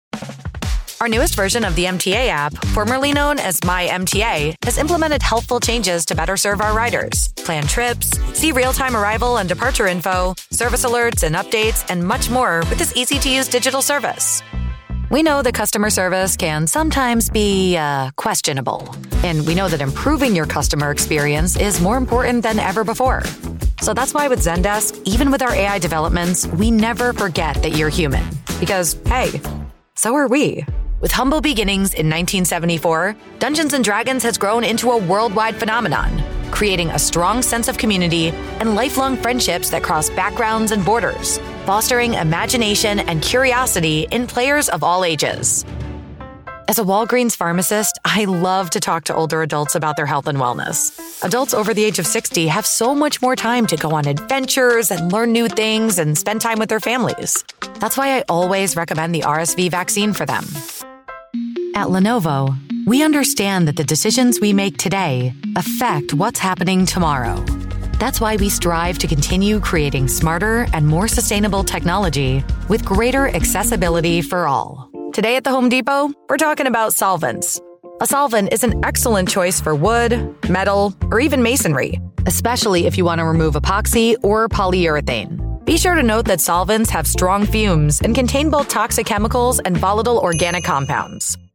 Corporate Videos
Microphone: Sennheiser 416
English (American)
Young Adult
Middle-Aged
Contralto